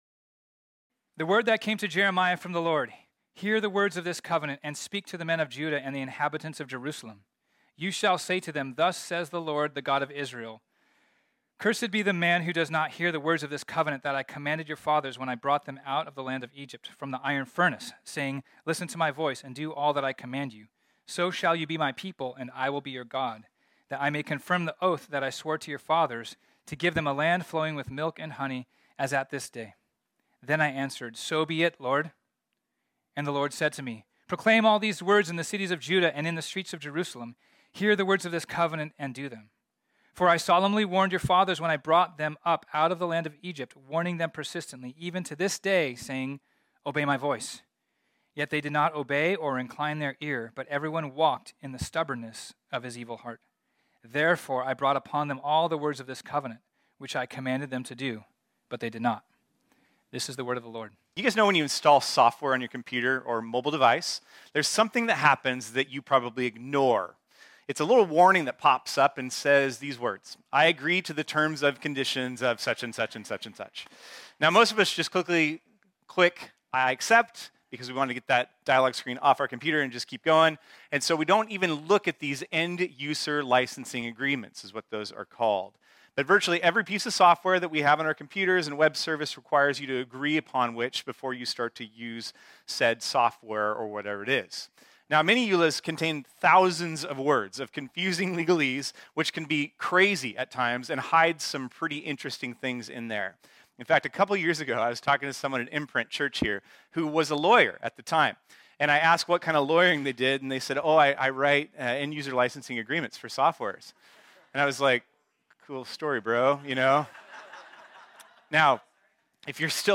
This sermon was originally preached on Sunday, May 22, 2022.